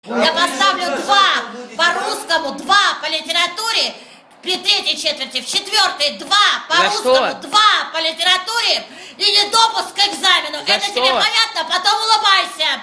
Звуки с фразами учителя: Голос строгой учительницы